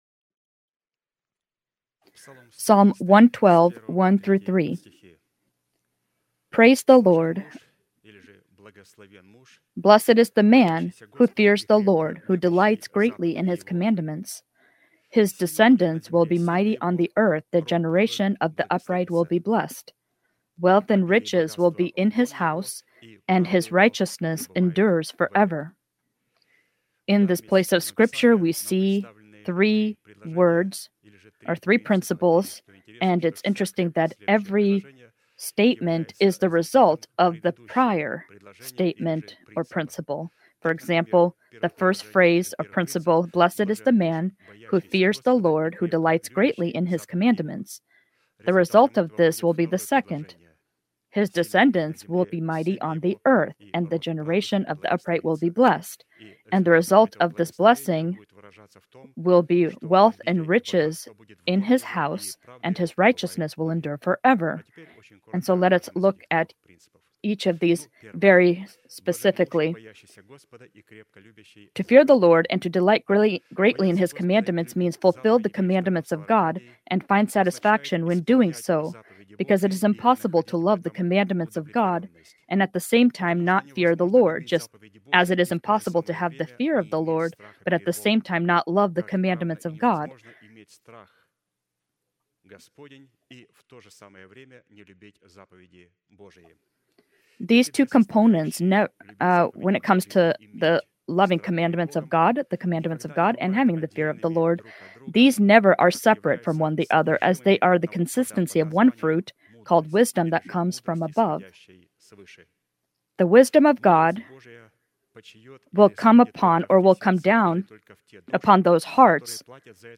Sermon title: